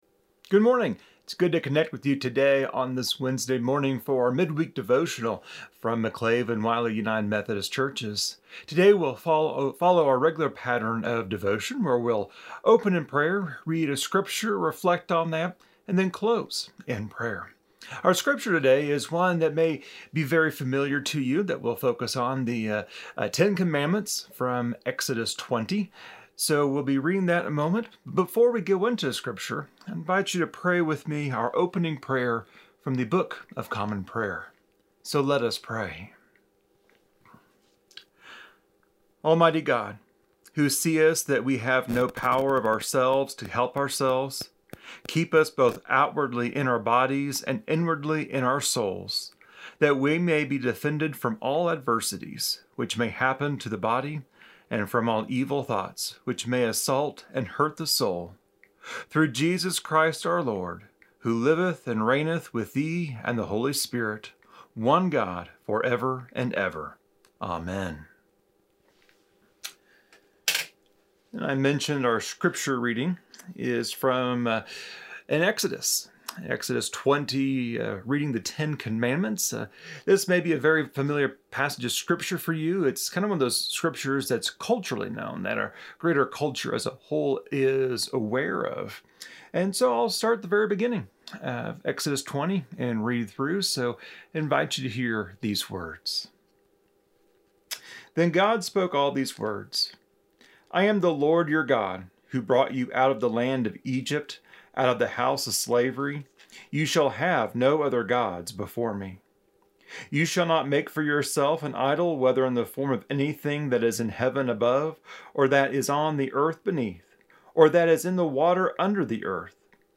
Wednesday Devotional: Nurturing Relationships